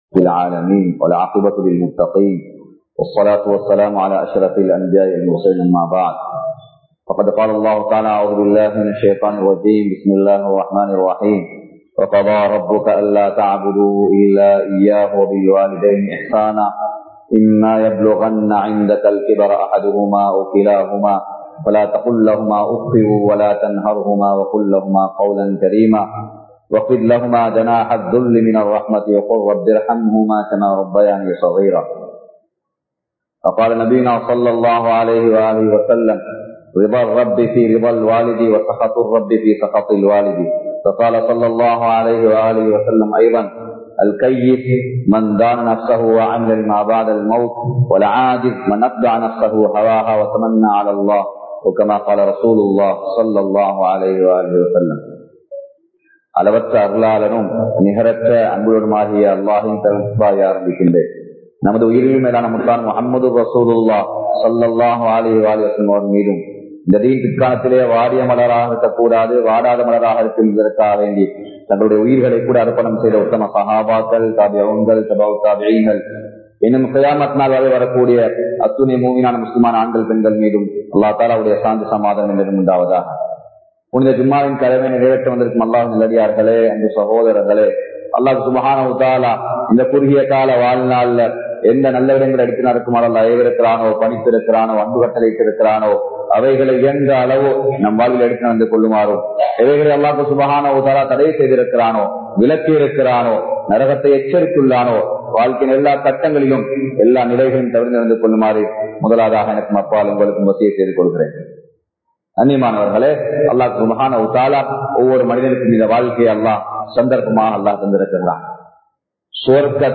உங்களது பெற்றோர்கள் உங்களது சுவர்க்கமாகும் | Audio Bayans | All Ceylon Muslim Youth Community | Addalaichenai
Zaviyathul Khairiya Jumua Masjidh